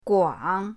guang3.mp3